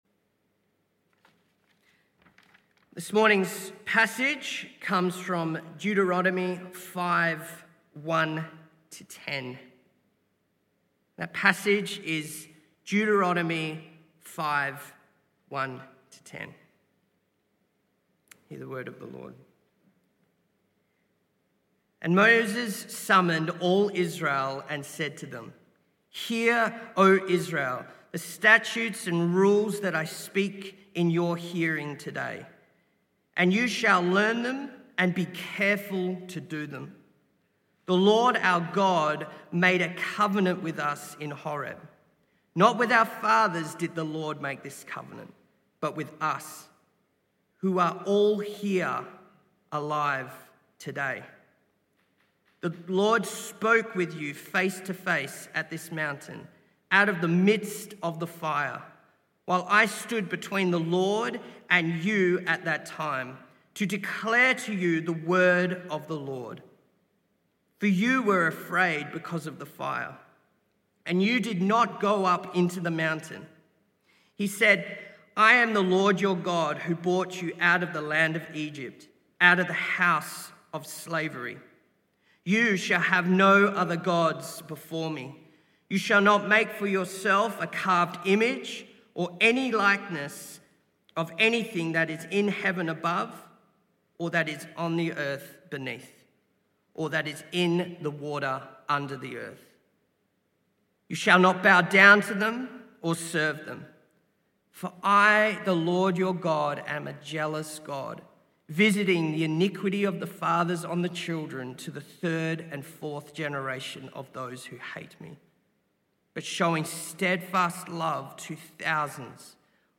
This talk was part of the AM Service series entitled Freedom: Understanding The 10 Commandments.